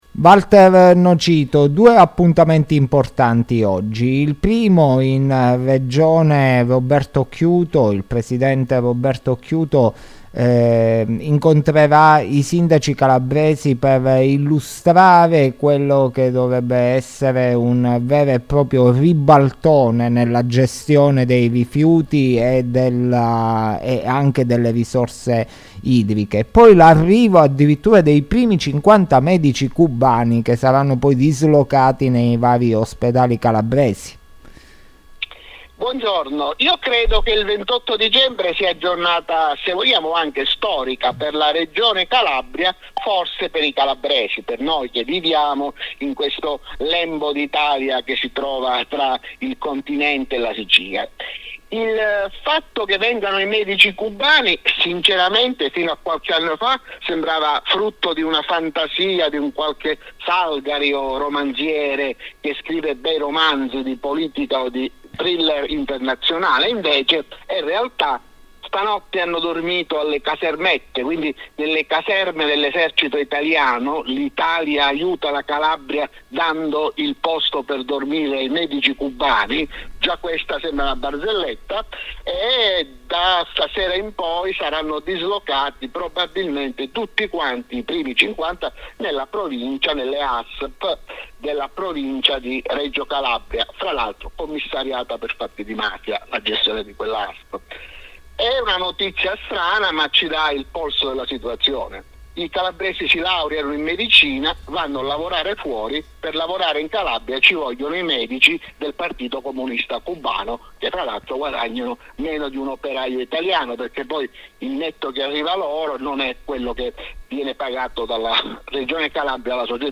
Interviste & Podcast